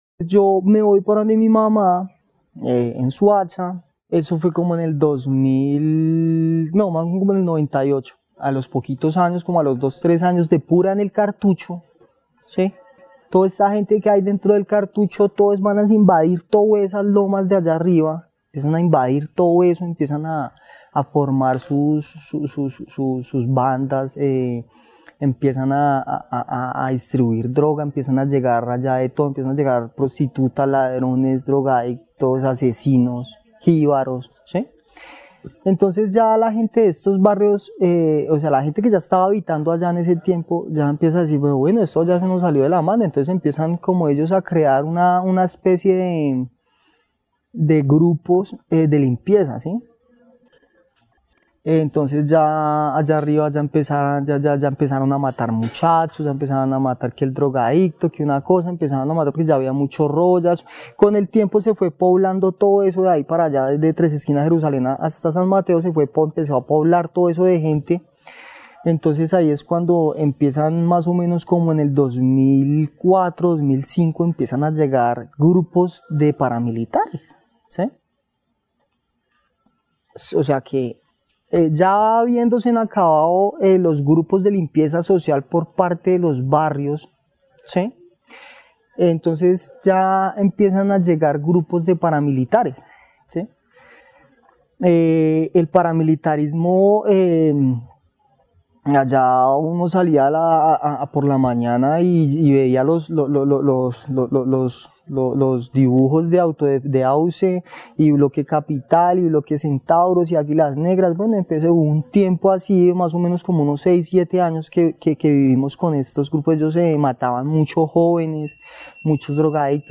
Testimonio de habitante de calle sobre el funcionamiento y control de la zona conocida como El Cartucho y los grupos que allí hacían vida. El testimonio fue grabado en el marco de los Clubes de Memoria y su participación en el programa Patrimonio y Memoria, de la línea de Espacios Creativos de BibloRed.